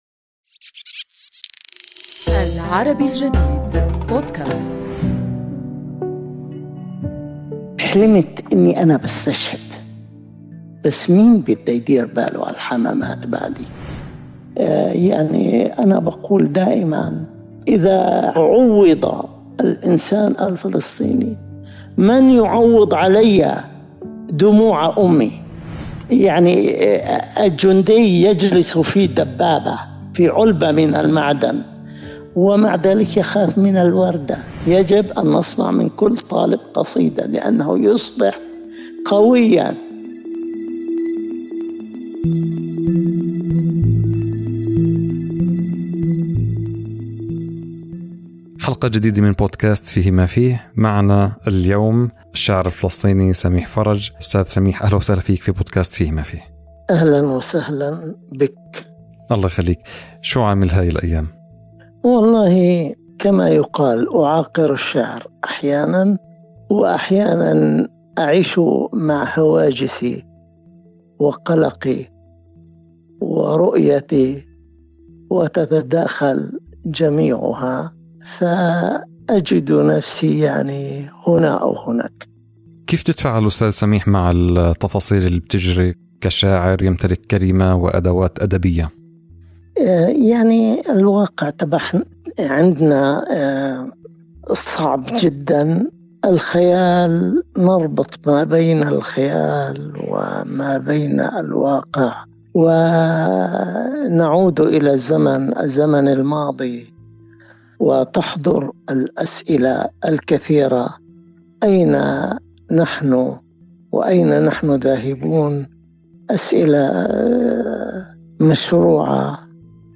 حوارنا اليوم